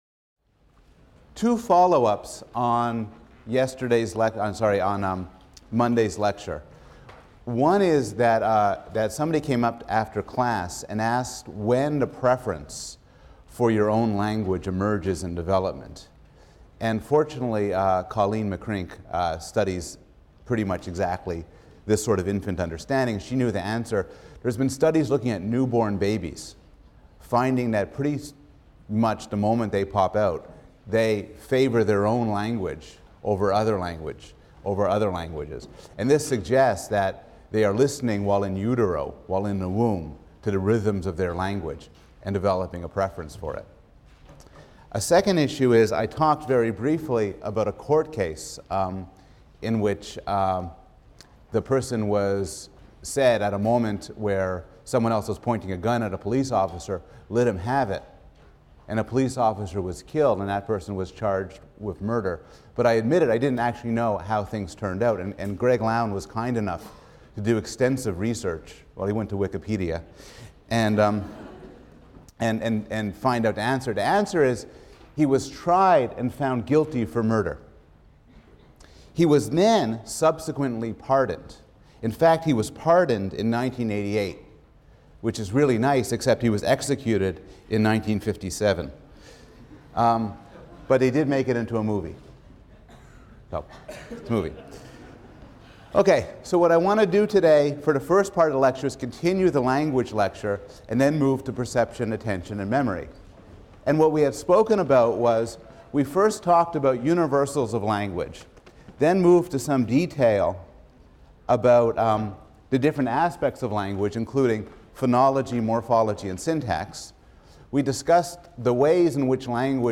PSYC 110 - Lecture 7 - Conscious of the Present; Conscious of the Past: Language (cont.); Vision and Memory | Open Yale Courses